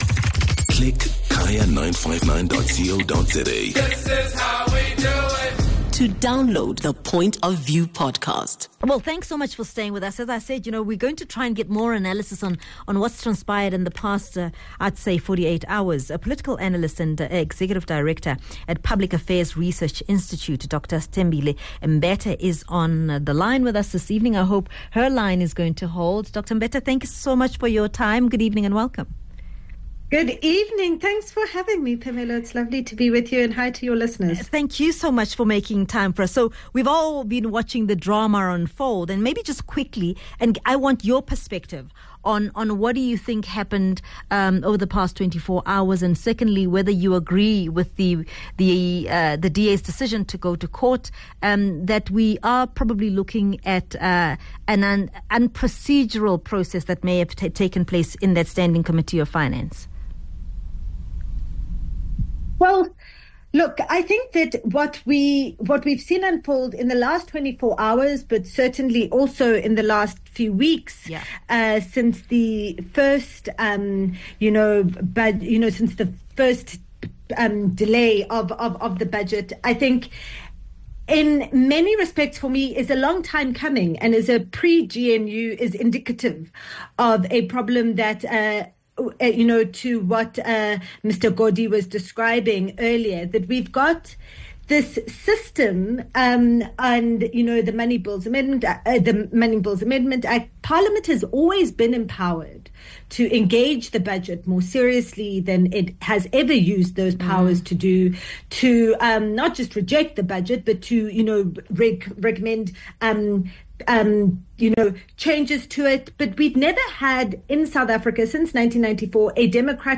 3 Apr Analysis: GNU at risk after parliament backs budget